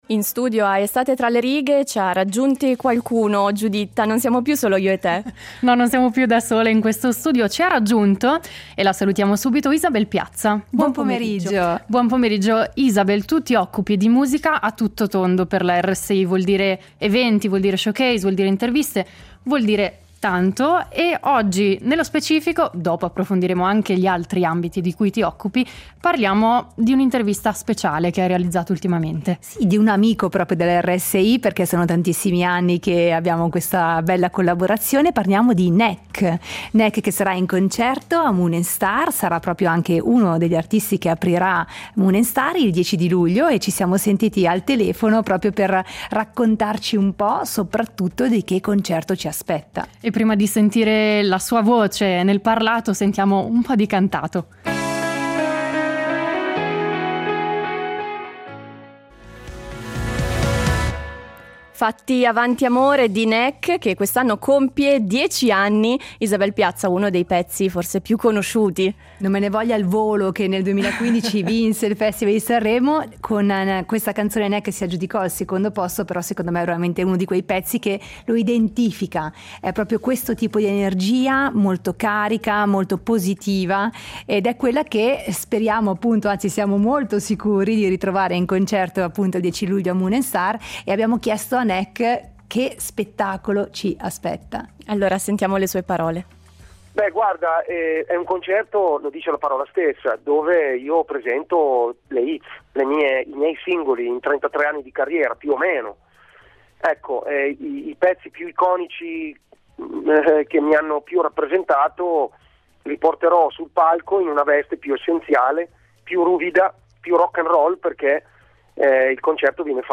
ha intervistato il cantautore italiano